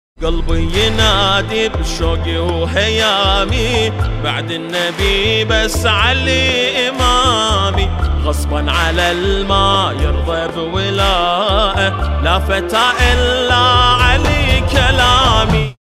زنگ موبایل
رینگتون موبایل غدیری و با نشاط
(باکلام عربی)